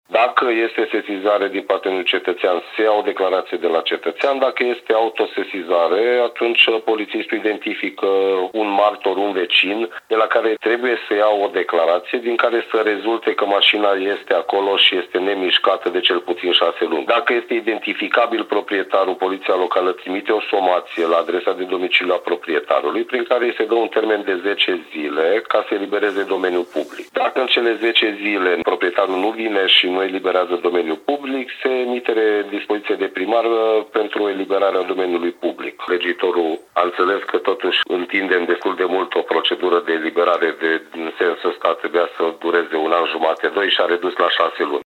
Șeful Poliției Locale Timișoara, Atilla Hajdu, a explicat că mașinile abandonate sunt de două feluri: cu proprietar cunoscut și cu proprietar neidentificat. Procedura de ridicare a fost simplificată astfel încât eliberarea domeniului public să se poată face mai rapid.